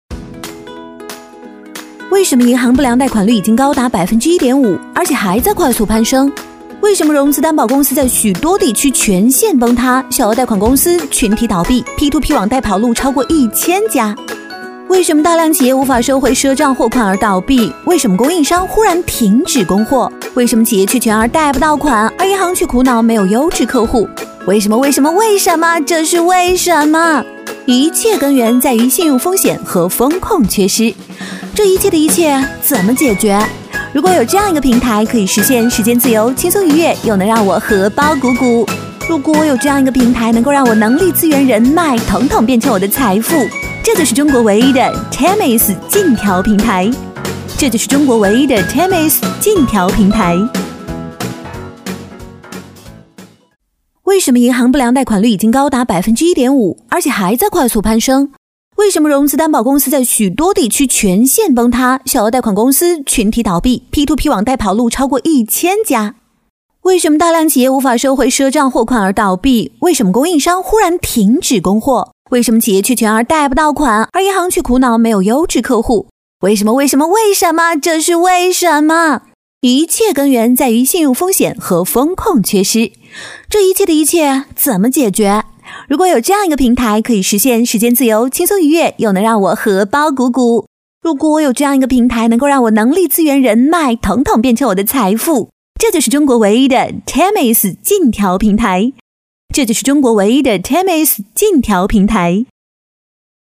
国语青年沉稳 、积极向上 、时尚活力 、调性走心 、亲切甜美 、女宣传片 、80元/分钟女S143 国语 女声 电台-都市夜未眠-自然柔和 沉稳|积极向上|时尚活力|调性走心|亲切甜美